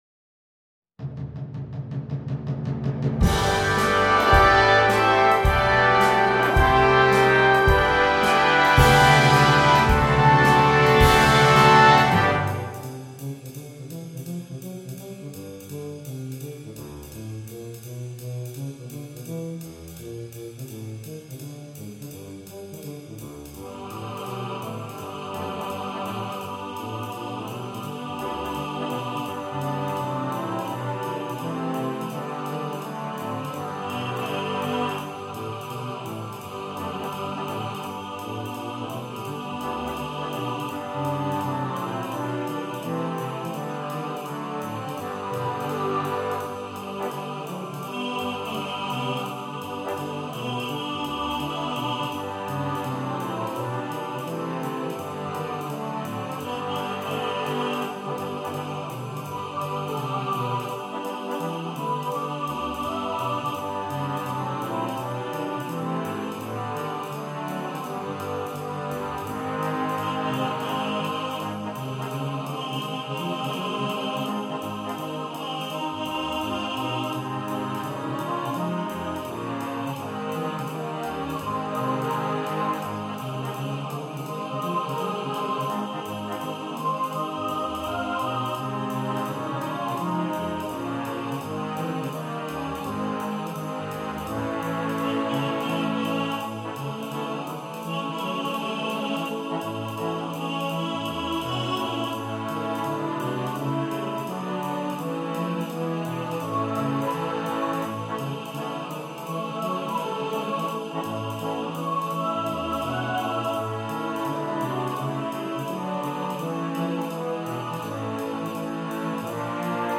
traditionellen Gospel
Besetzung: Vocal Duet, Choir & Brass Band